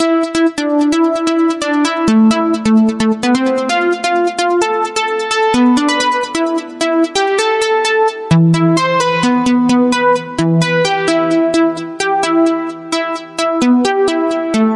描述：序列短语我在FL工作室程序，Harmor合成器软件，循环。一种弹拨的吉他即兴演奏。
Tag: FL 循环 音乐合成器 工作室 TECHNO 合成器 吉他 即兴重复段 序列 循环 短语